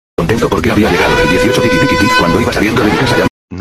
Goofy Ahh Siren Meme Sound Button - Free Download & Play